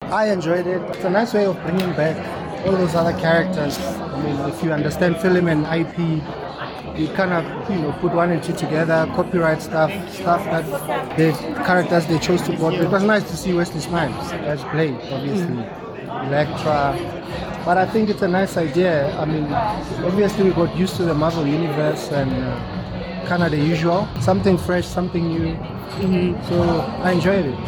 YNews also got an opportunity to speak to Award winning comedian, David Kau, who was also at the pre-screening.